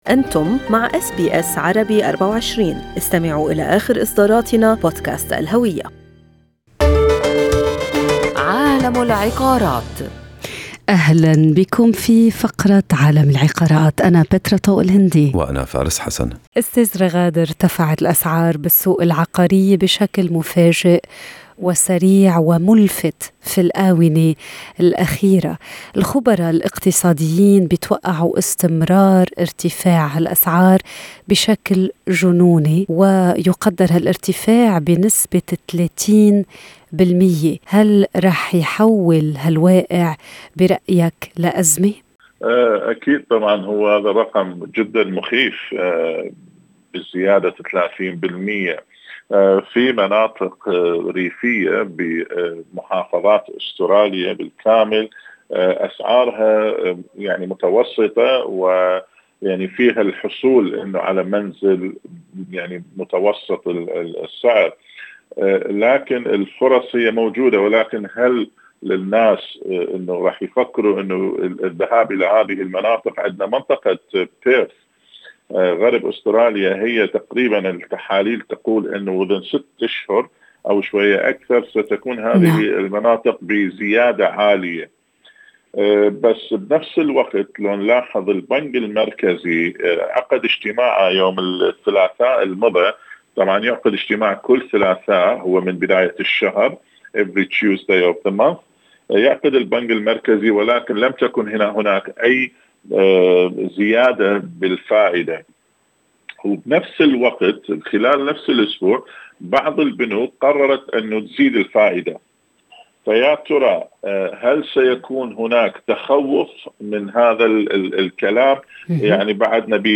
لمعرفة المزيد عمّا جرى بحثه، يمكنك الاستماع الى كامل المقابلة في الملف الصوتي أعلاه.